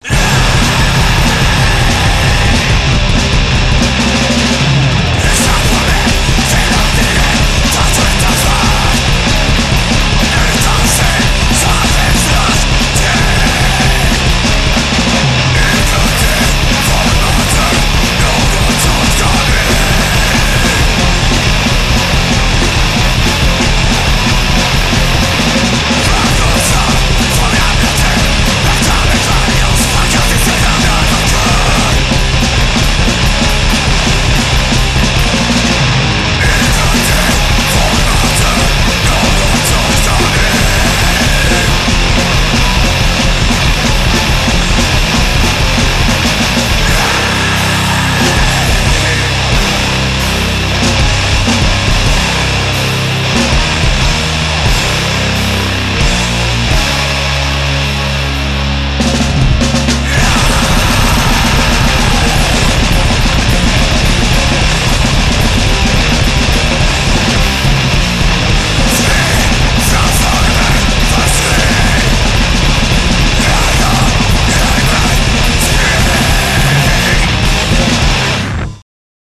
dual male-female unstopable crustcore onslaught